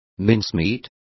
Complete with pronunciation of the translation of mincemeat.